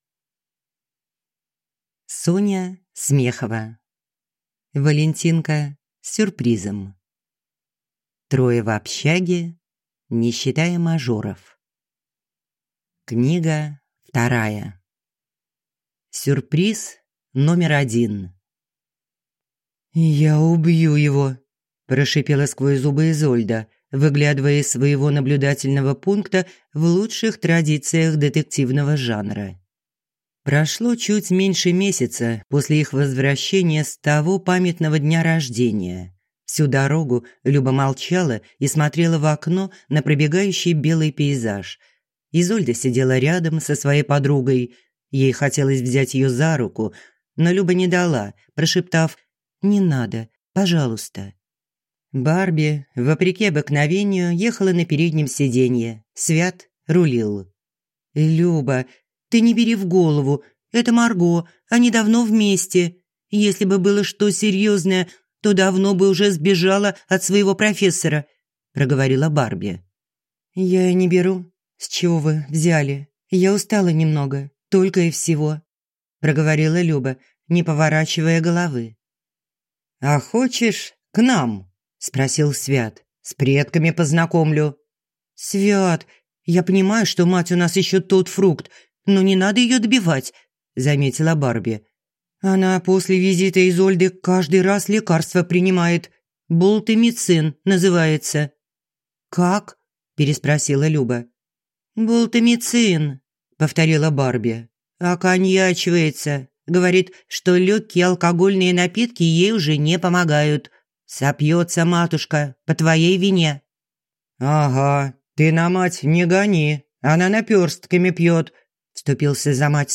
Аудиокнига Валентинка с сюрпризом | Библиотека аудиокниг
Прослушать и бесплатно скачать фрагмент аудиокниги